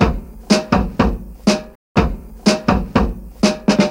• 122 Bpm Drum Groove B Key.wav
Free drum loop sample - kick tuned to the B note. Loudest frequency: 1048Hz
122-bpm-drum-groove-b-key-xOY.wav